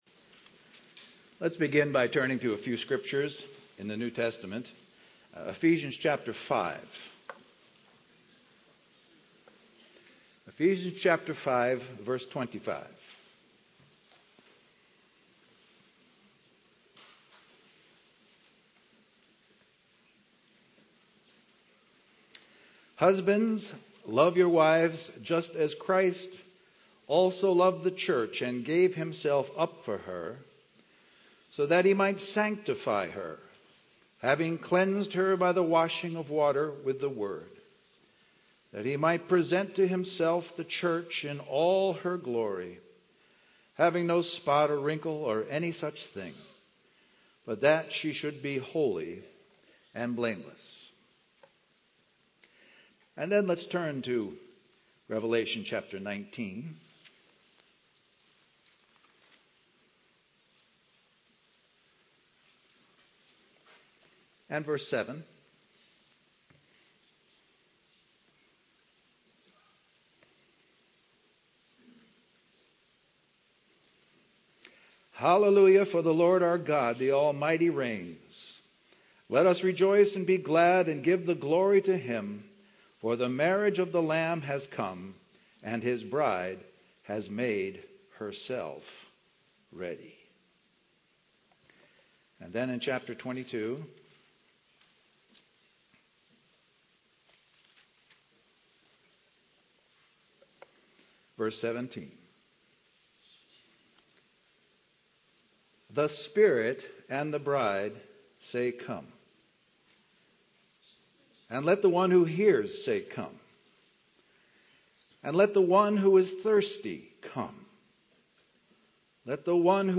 A collection of Christ focused messages published by the Christian Testimony Ministry in Richmond, VA.
Christian Family Conference